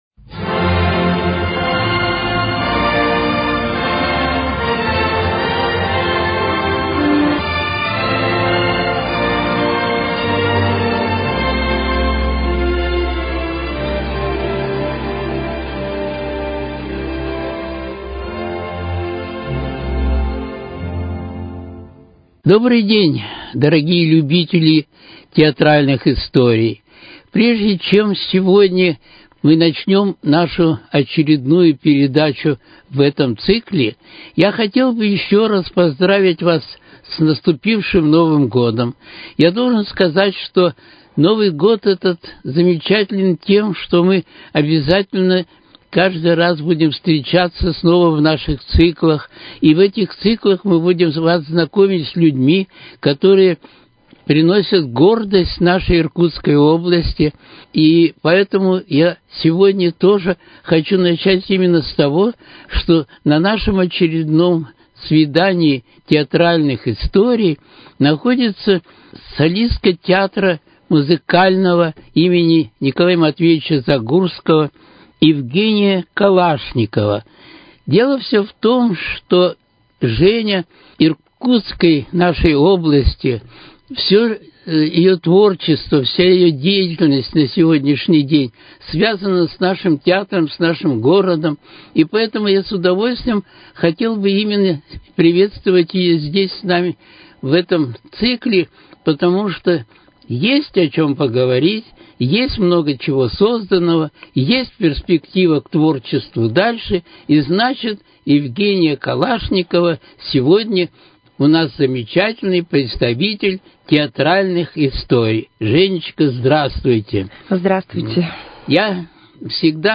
В этом выпуске беседа с солисткой Иркутского музыкального театра им.